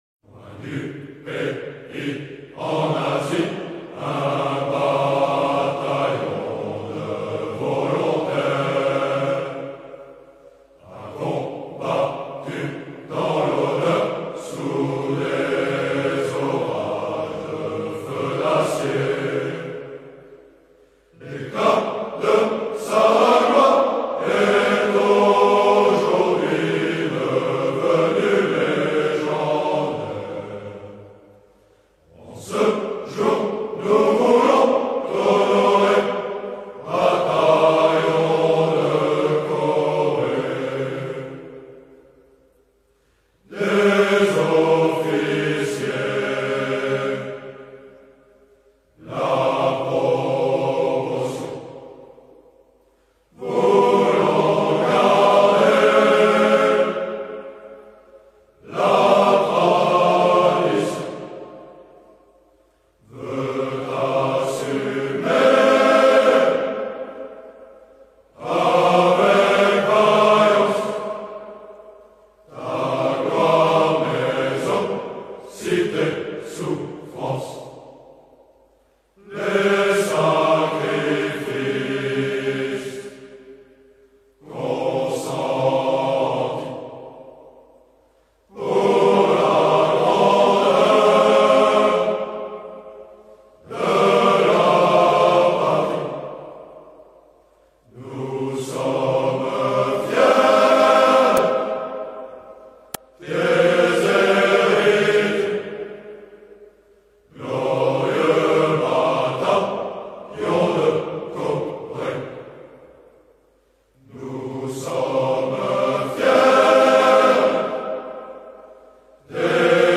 Bataillon de Corée : Chant de promotion de l’EMIA (1989-1991)